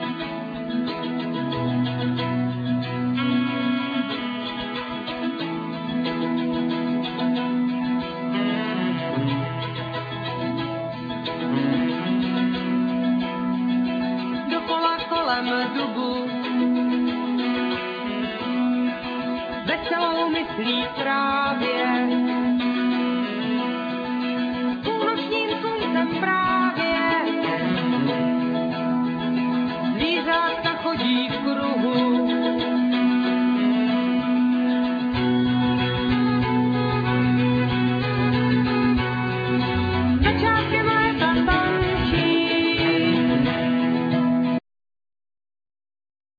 Vocal,Violin,Okarina
Mandolin,Guitar
Saxophone,ClarinetXylophone,Bonga
Cello,Violin